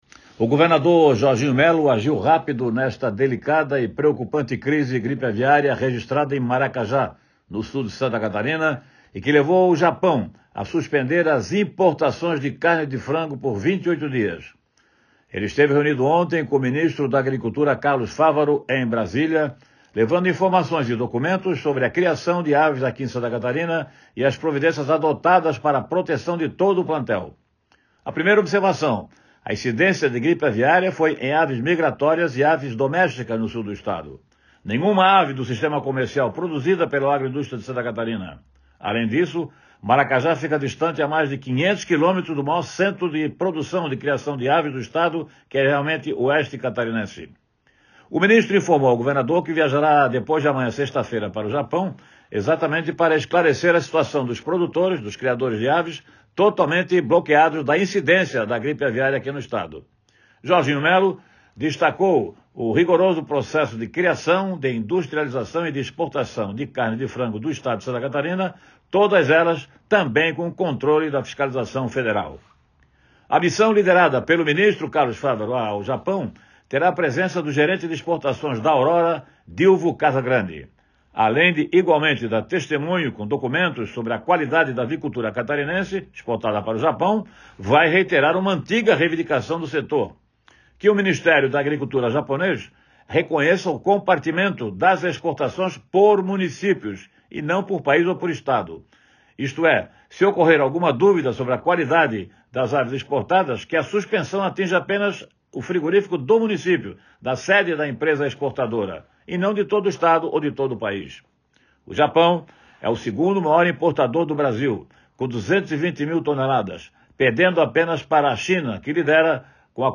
Confira o comentário na íntegra